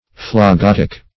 phlogotic - definition of phlogotic - synonyms, pronunciation, spelling from Free Dictionary Search Result for " phlogotic" : The Collaborative International Dictionary of English v.0.48: Phlogotic \Phlo*got"ic\, n. (Med.)